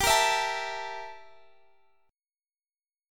Gm6add9 Chord (page 2)
Listen to Gm6add9 strummed